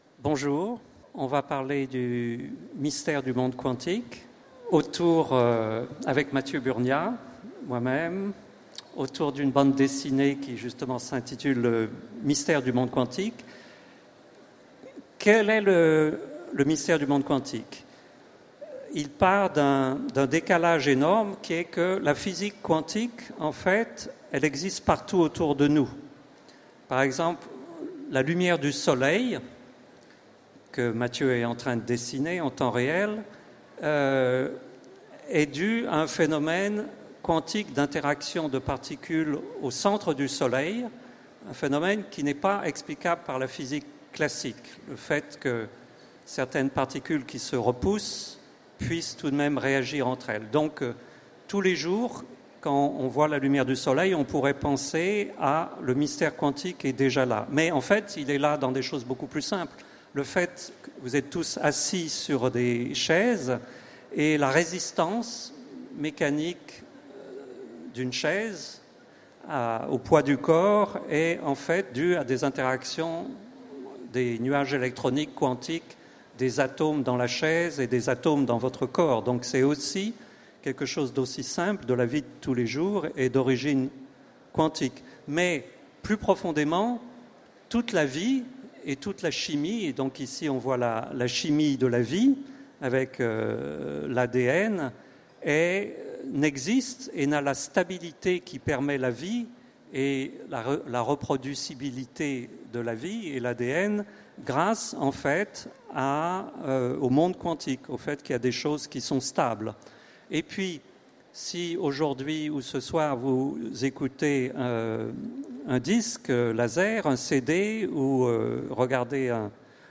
Utopiales 2016 : Conférence Le mystère du monde quantique